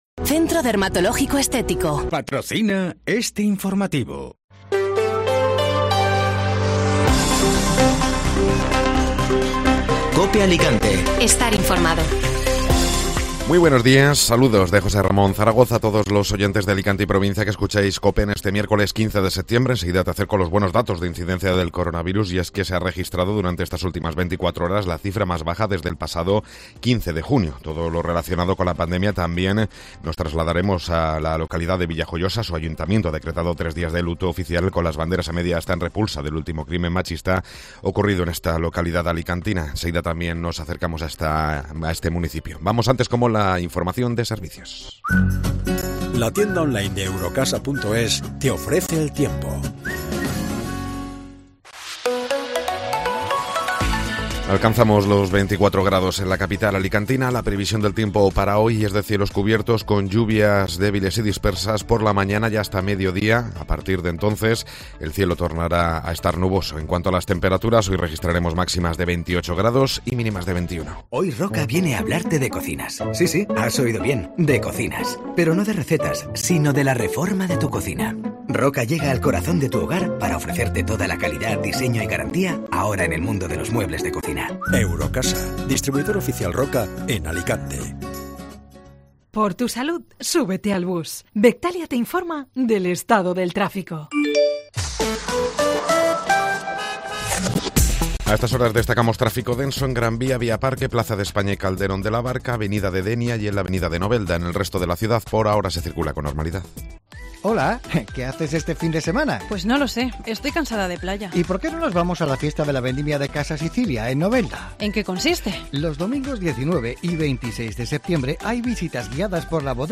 Informativo Matinal (Miércoles 15 de Septiembre)